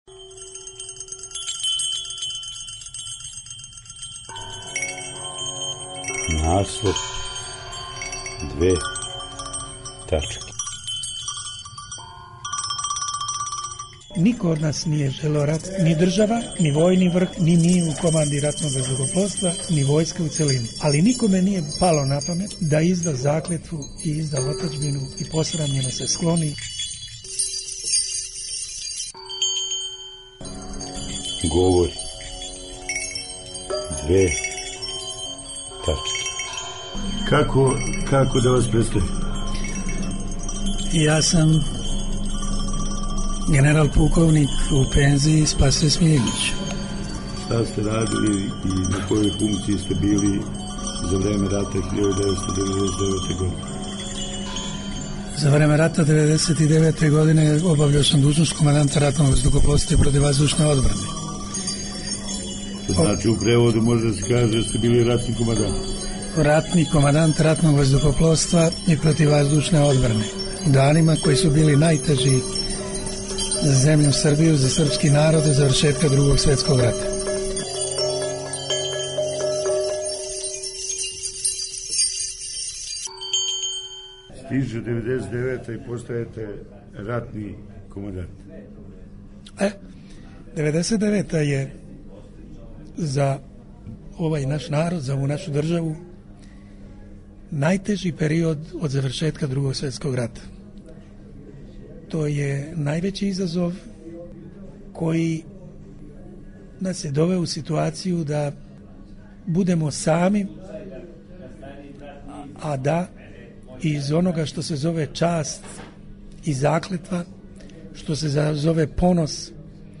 Документарни програм
Говори ратни командант РВ и ПВО генерал-пуковник Спасоје Смиљанић.